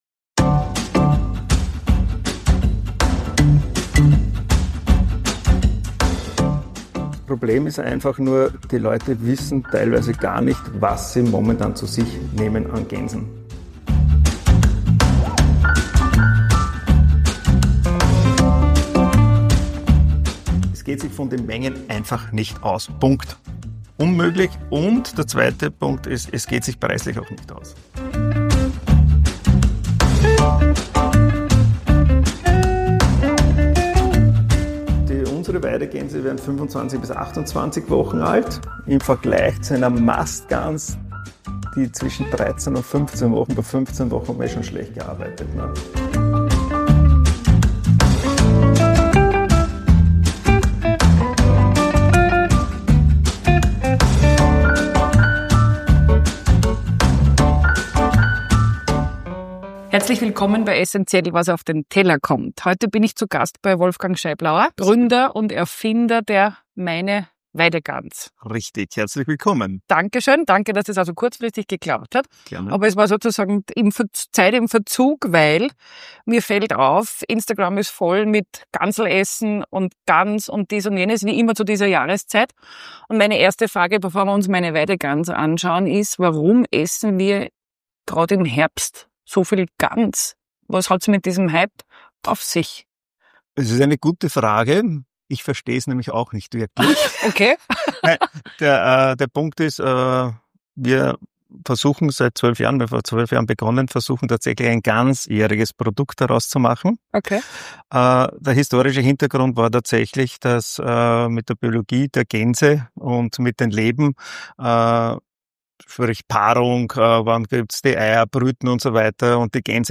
Ein Gespräch über den alljährlichen Herbst-Hype rund um die Gans, über Qualitäts-Standards, die über Bio hinausgehen, und über die Frage, wie alt Gänse werden.